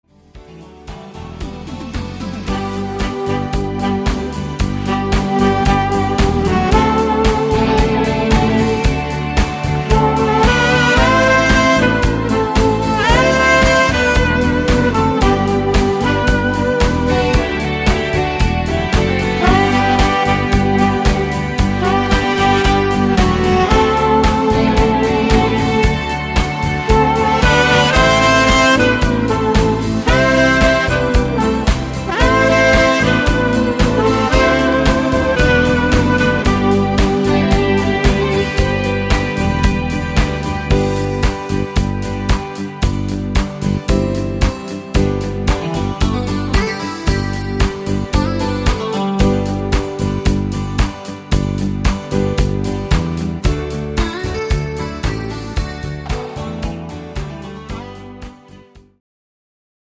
Discofox on Sax